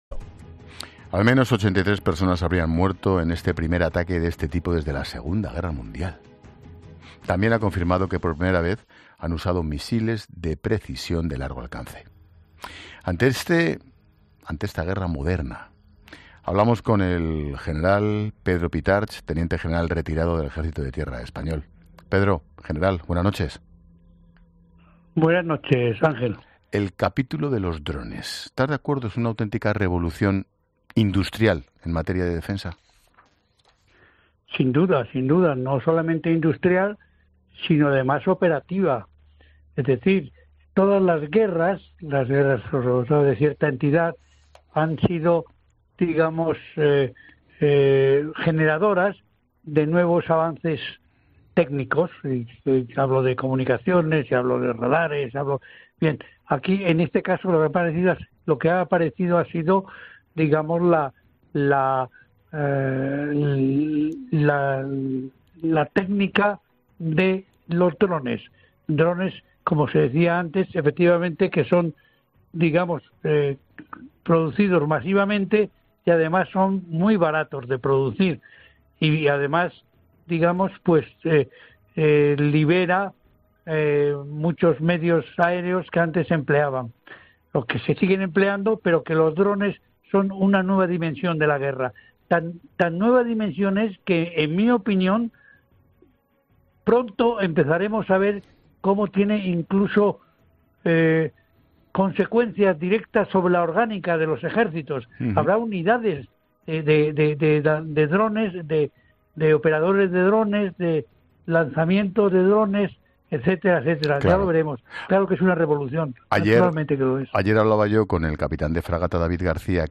Expósito entrevista al general Pedro Pitarch